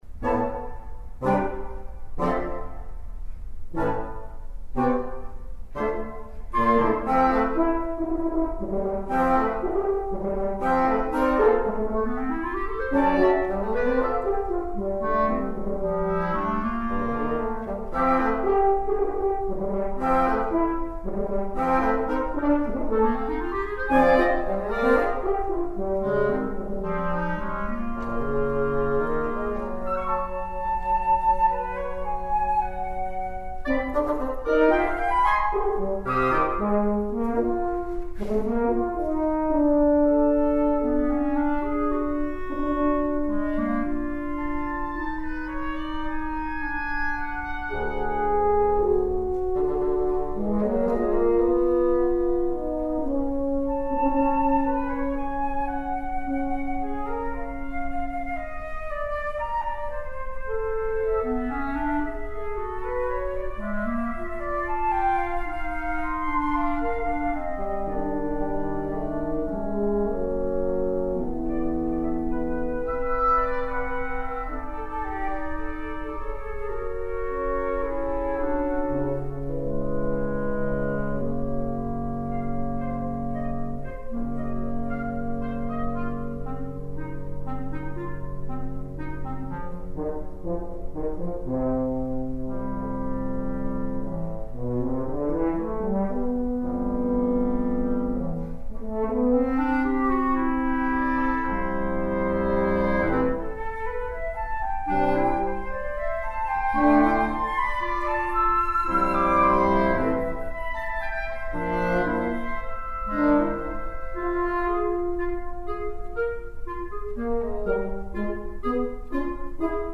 flute, oboe, Bb clarinet, bassoon, F horn